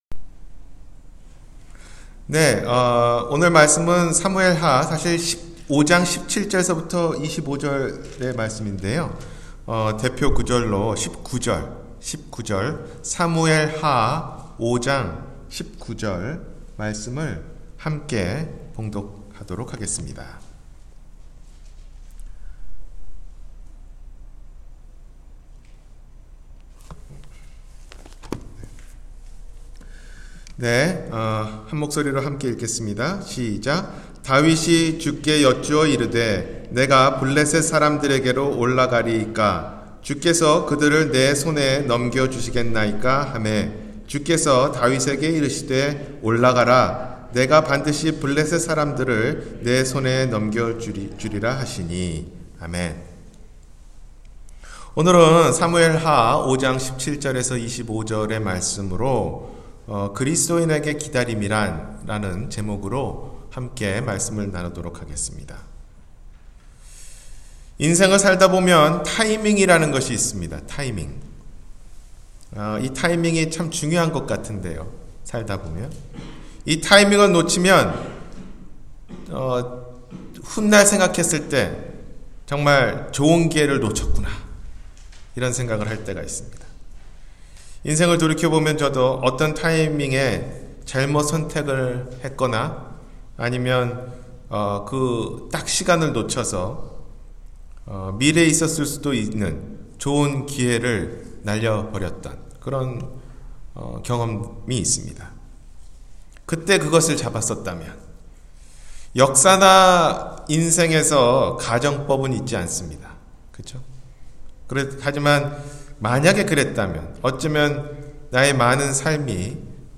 그리스도인에게 기다림이란 – 주일설교